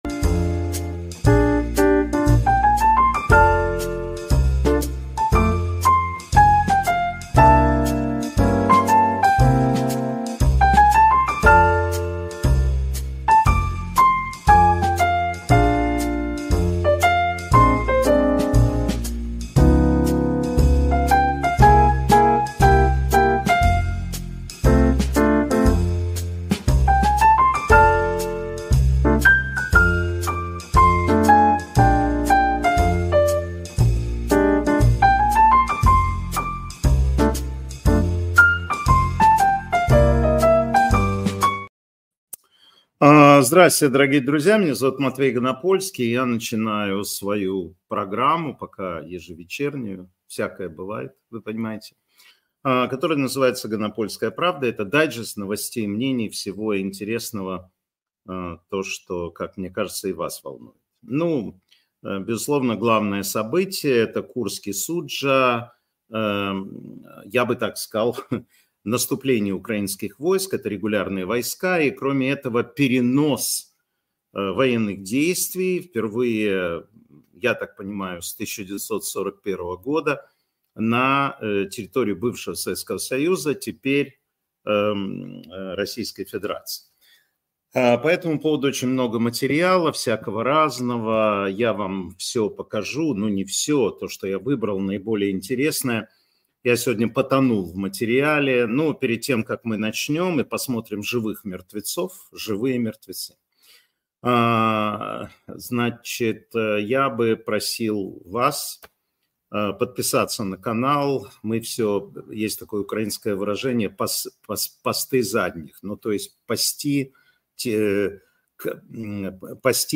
Эфир Матвея Ганапольского.